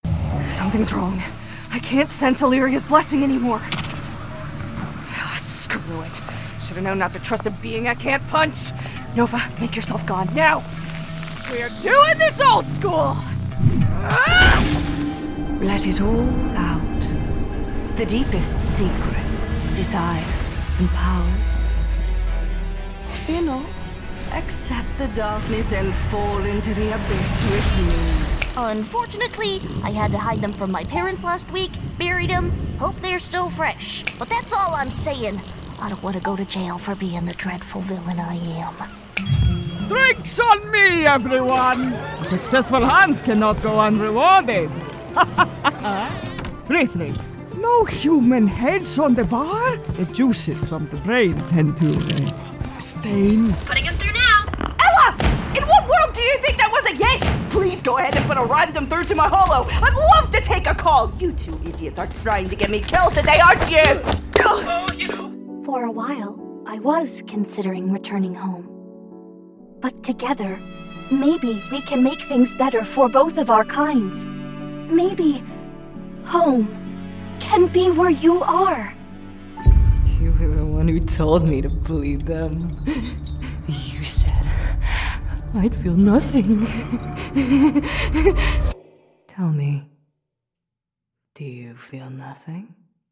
Voice Actor